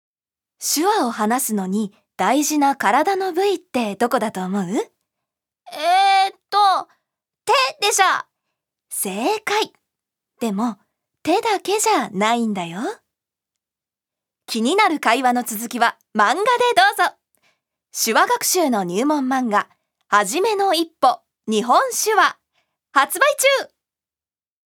預かり：女性
ナレーション４